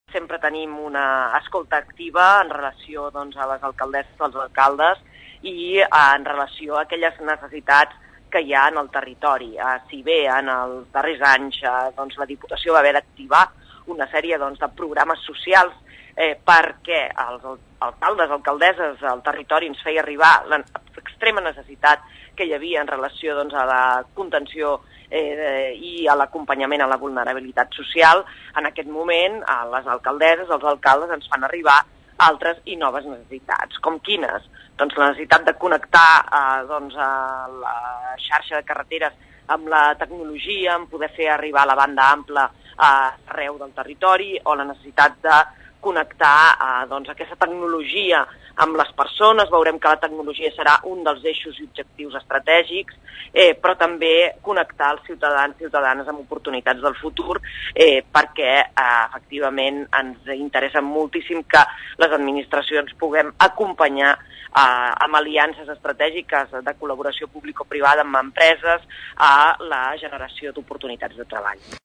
La presidenta de la Diputació i alcaldessa de Sant Cugat del Vallès, Mercè Conesa, n’ha parlat a la Xarxa de Comunicació Local.